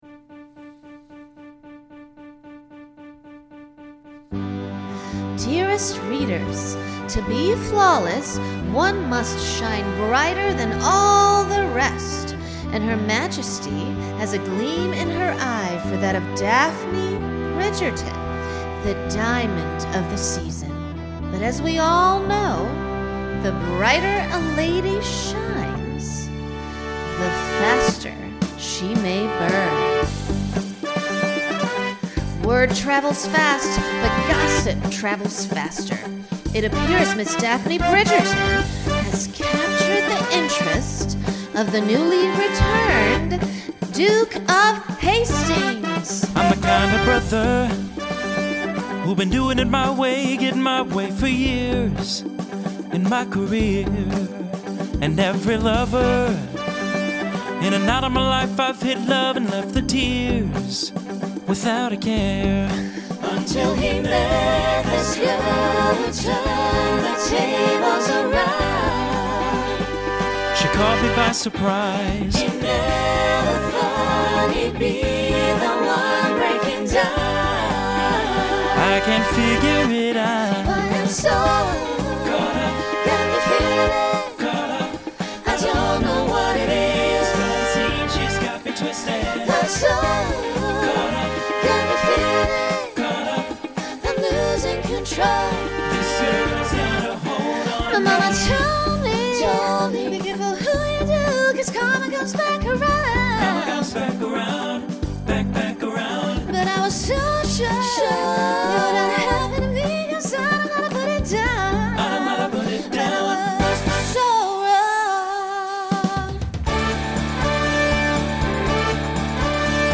Instrumental combo Genre Pop/Dance
Story/Theme Voicing SATB